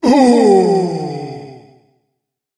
Die SFX
死亡音效
CR_monk_die_01.mp3